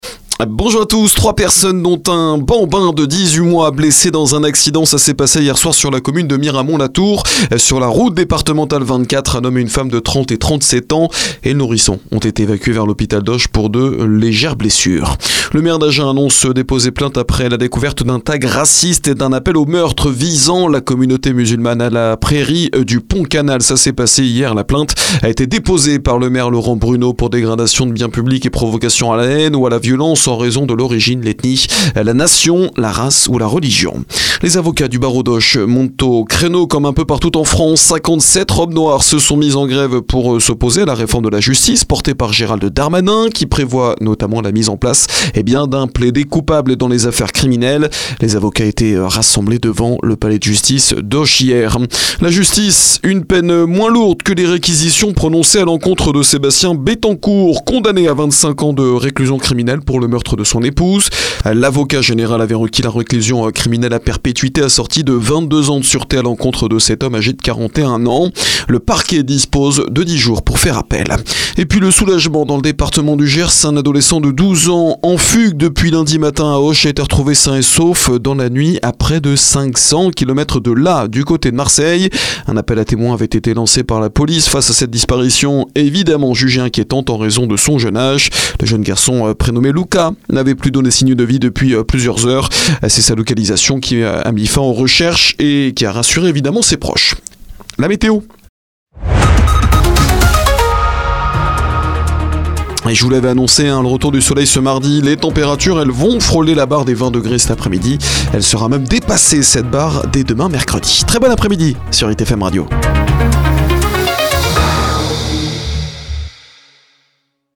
Flash Gers 14 avril midi